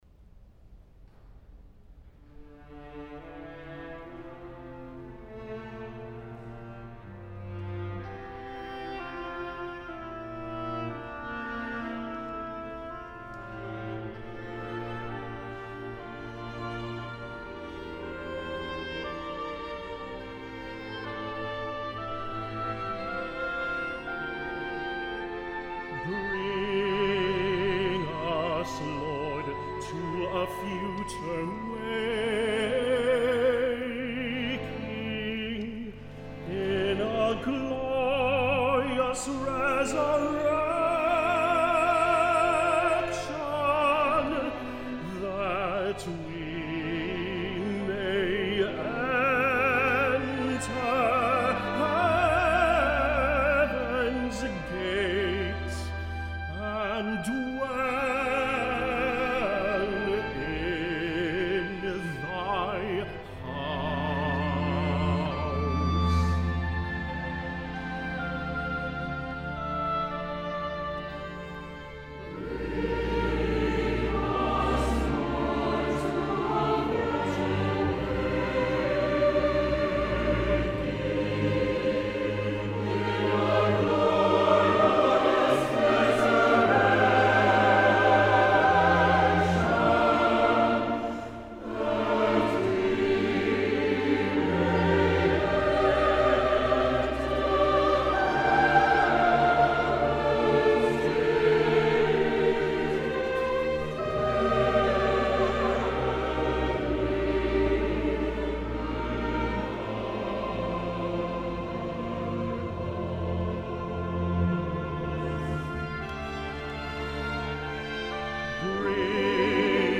Scored for tenor solo, SATB, oboe and organ